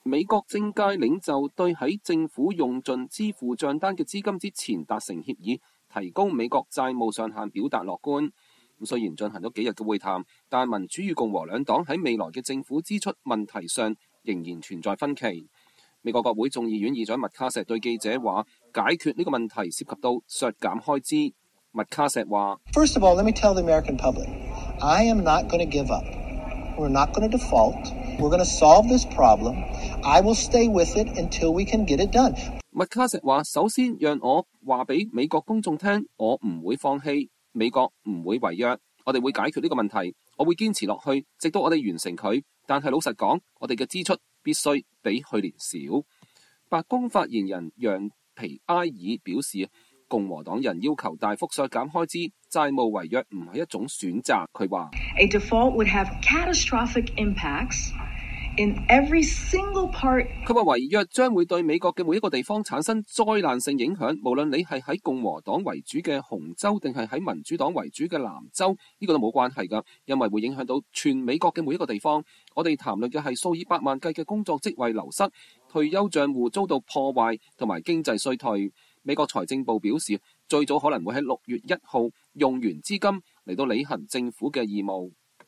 美國國會眾議院議長麥卡錫對記者談論國債上限問題